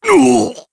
Dakaris-Vox_Damage_jp_03_b.wav